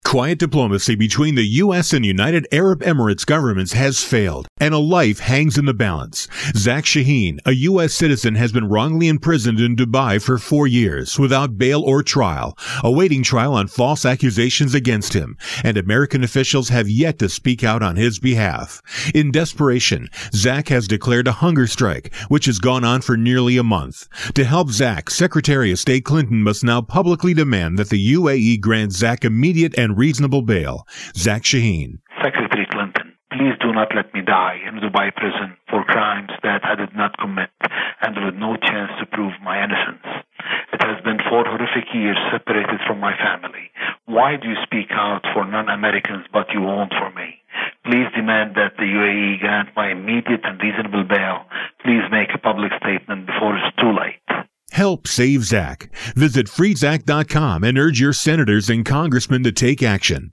June 11, 2012Posted in: Audio News Release